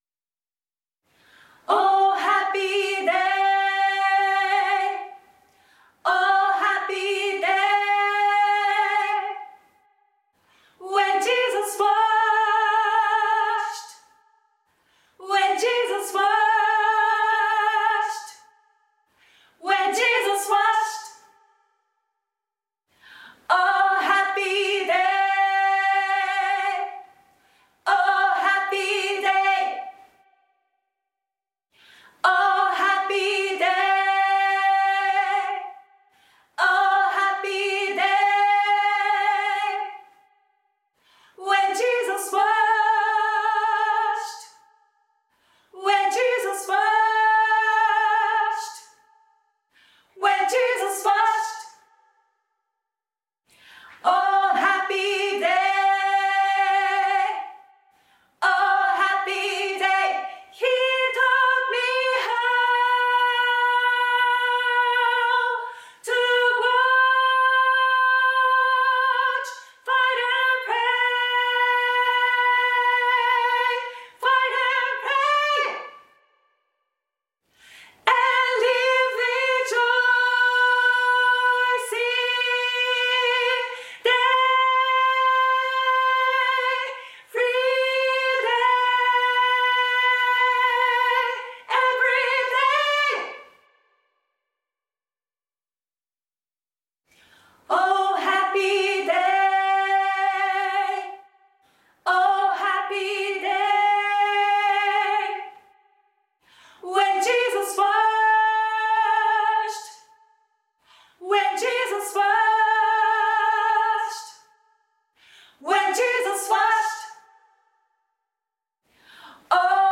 ミキシングのアルゴリズムの研究や，音楽合奏のモデル化の研究を行うためには，クロストークの無いセパレート音源の音楽データベースが必要となります． 特に，声楽については，過去にそのようなものは存在しませんでした．そこで，ミキシングの研究者自らが作った研究用データベースが， セパレート音源型音楽データベース SIS-DB です．
このページは，SIS-DB のダウンロードページです．表内の各ファイルは，Microsoft WAVE形式 (.wav ファイル) にて提供しております．また，一括ダウンロード用のファイルは，ZIP形式にて圧縮してあります．音声データは，すべて PCM 48,000 Hz 24bit に変換しておりますが，収録時のフォーマットはファイルにより異なります．チャネル数は，モノラル(1ch) となっております．
2. プロ歌唱者2名ずつによる「OH HAPPY DAY」のパート別歌唱
ソプラノ   一括ダウンロード
（収録時）WAVE/48 kHz/24 bit
なお，音楽データ２は，リモート合唱のガイドボーカルとして使用されたものである．
MU02_SOP_01.wav